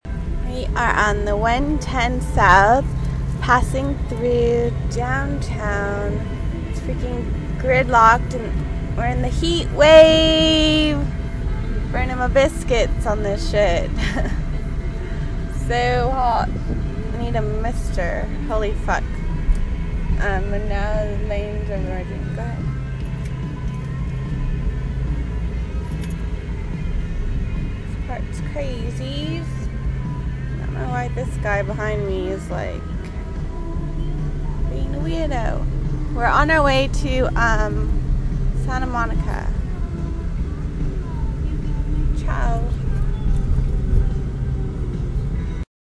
Downtown Traffic
7601-downtown-traffic.mp3